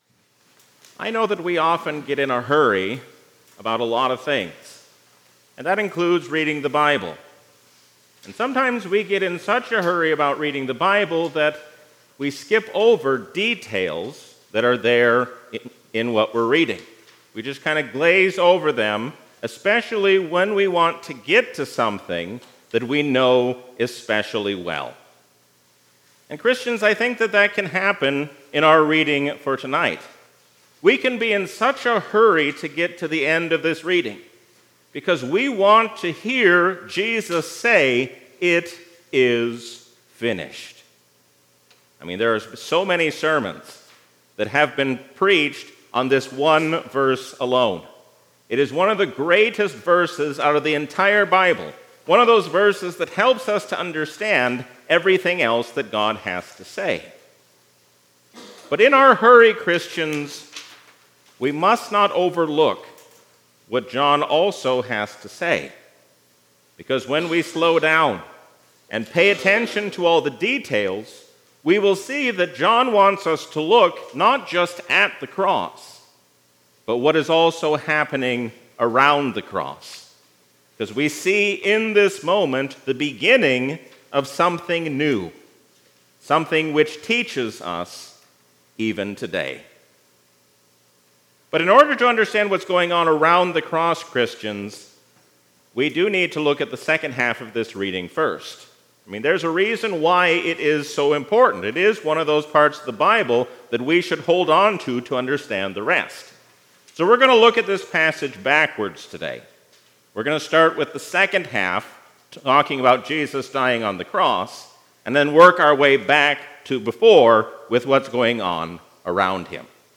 A sermon from the season "Trinity 2024." Let us seek to resolve our disputes in true unity and peace, because God has made us one in Jesus Christ.